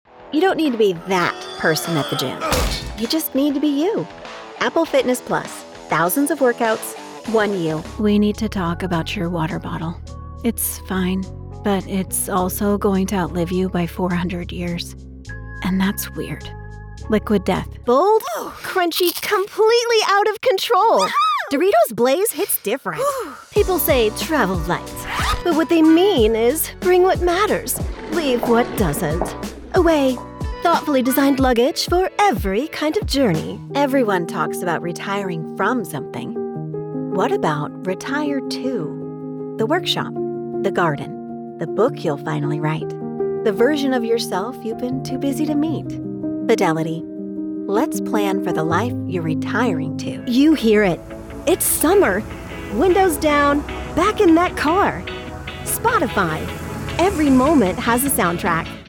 Never any Artificial Voices used, unlike other sites.
Yng Adult (18-29) | Adult (30-50)